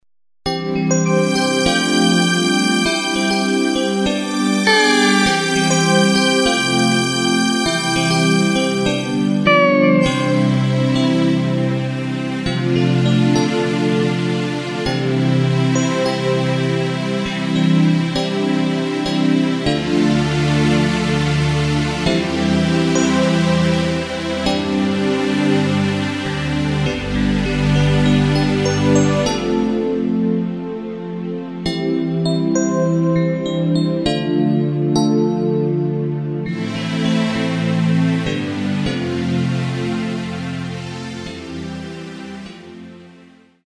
Takt:          4/4
Tempo:         100.00
Tonart:            C
Schlager aus dem Jahr 2005!
Playback mp3 Mit Drums